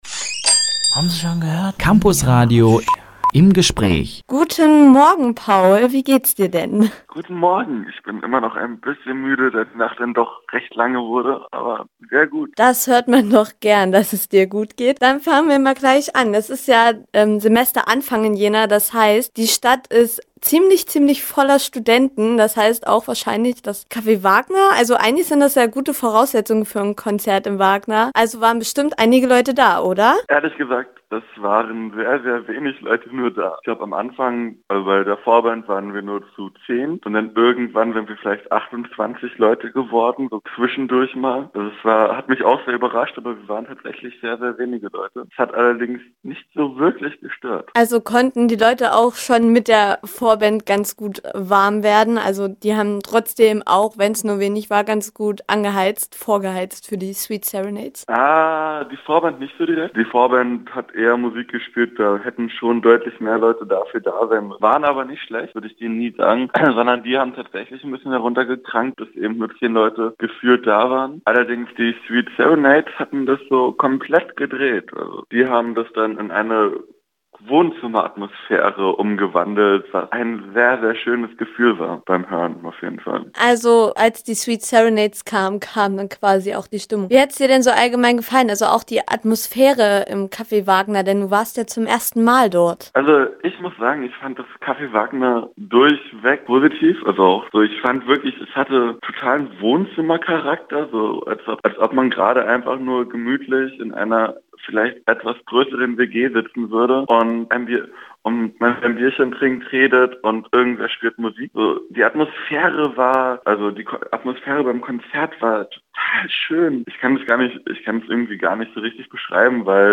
Rezension: The Sweet Serenades im Café Wagner – Campusradio Jena